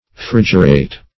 Frigerate \Frig"er*ate\, v. t.